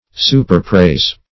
Search Result for " superpraise" : The Collaborative International Dictionary of English v.0.48: Superpraise \Su`per*praise"\, v. t. To praise to excess.